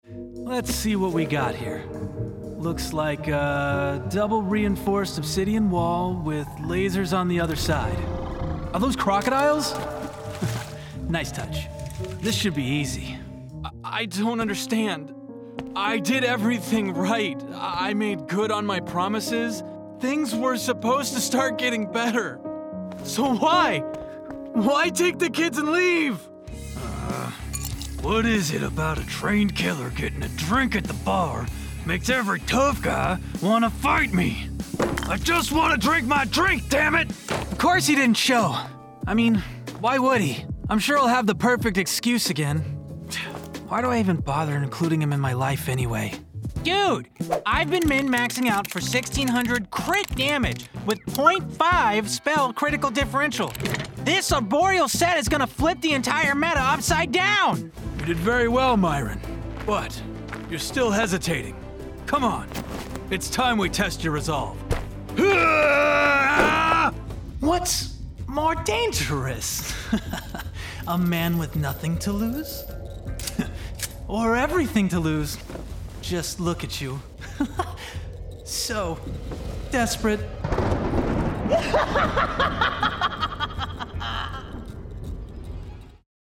Young Adult, Adult, Mature Adult
With an energetic tone,I specialize commercials, video games, and animation, Whether you need a friendly, conversational voice, or a determined protagonist, I deliver professionalism and versatility in every project.
Location: Atlanta, GA, USA Languages: english Voice Filters: VOICEOVER GENRE ANIMATION 🎬